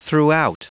Prononciation du mot throughout en anglais (fichier audio)
Prononciation du mot : throughout